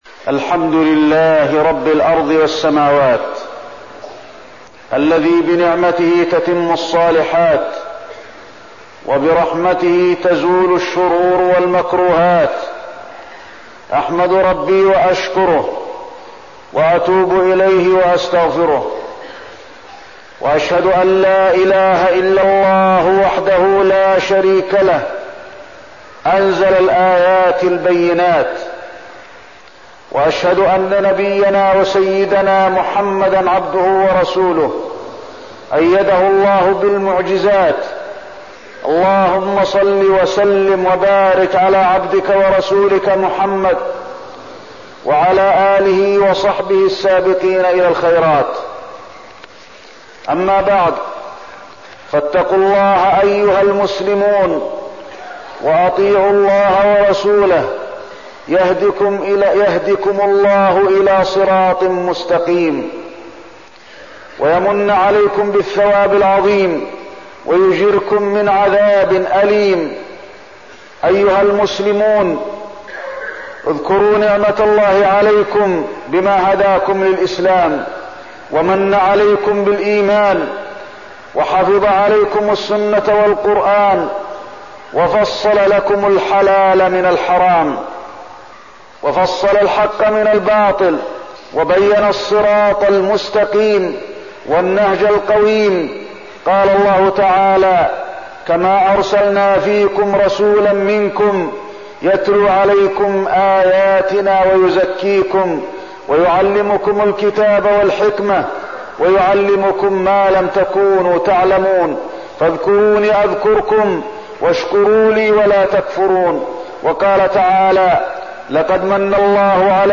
تاريخ النشر ١٧ شعبان ١٤١٤ هـ المكان: المسجد النبوي الشيخ: فضيلة الشيخ د. علي بن عبدالرحمن الحذيفي فضيلة الشيخ د. علي بن عبدالرحمن الحذيفي المعوقات عن الصراط المستقيم